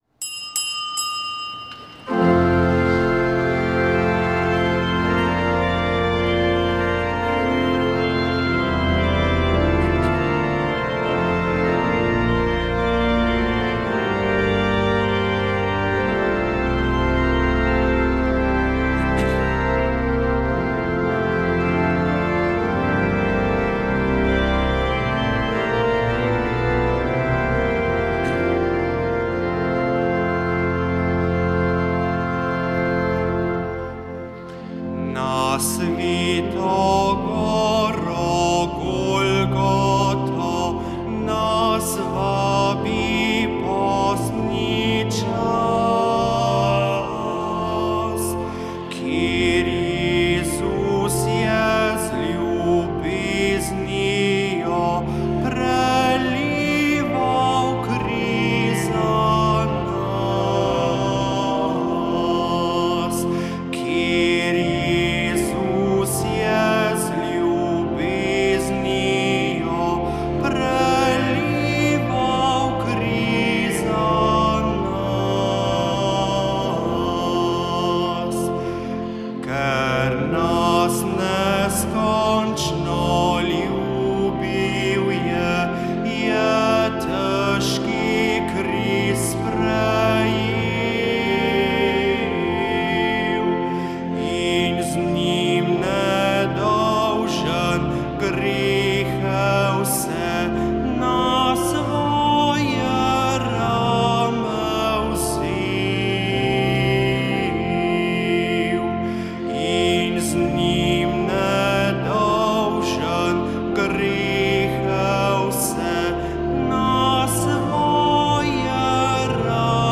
Sveta maša
Sv. maša iz stolnice svetega Nikolaja v Ljubljani 6. 5.
Sv. mašo je ob praznovanju 70-letnice izhajanja katoliškega tednika Družina daroval škof Anton Jamnik, pel je basbaritonist Marcos Fink, za orglami